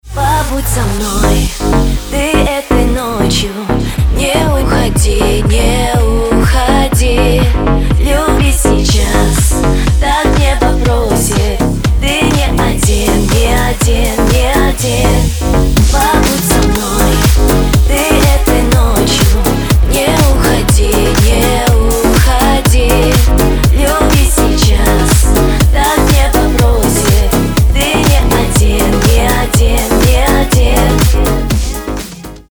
• Качество: 320, Stereo
deep house
Cover
Кавер микс